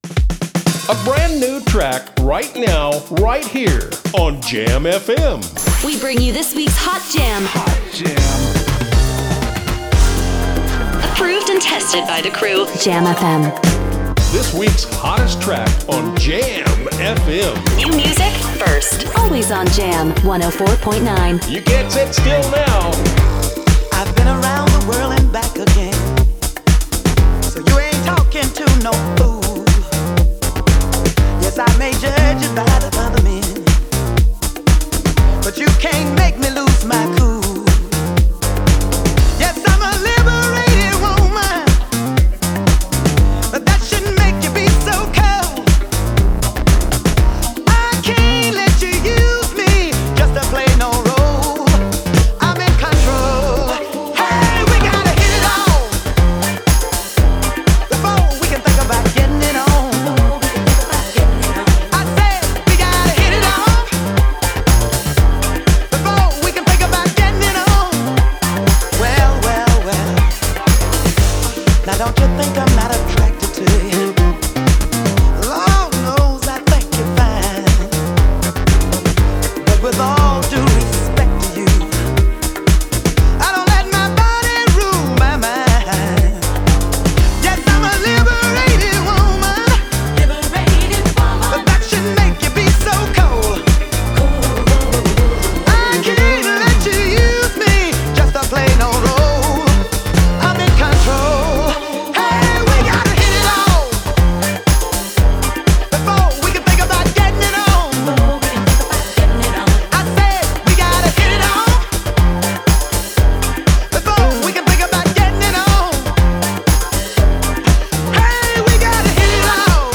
vernieuwde energie en dansbaarheid